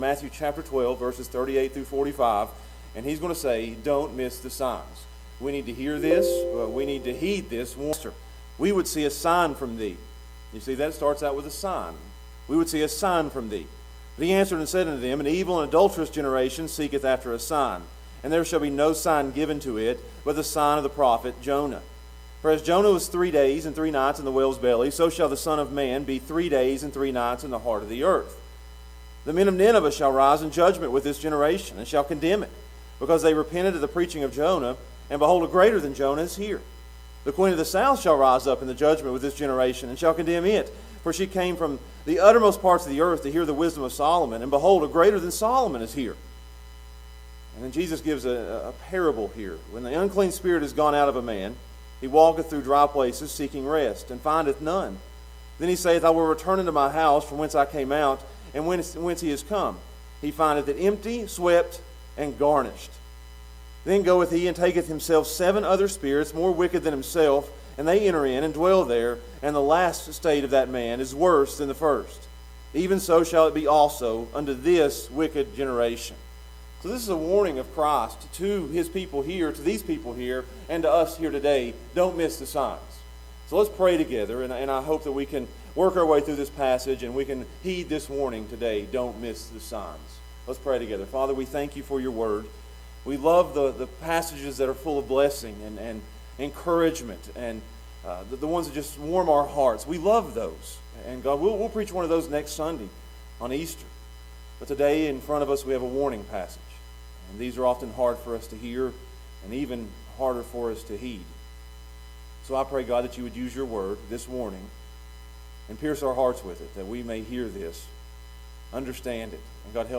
Don't Miss the Signs | SermonAudio Broadcaster is Live View the Live Stream Share this sermon Disabled by adblocker Copy URL Copied!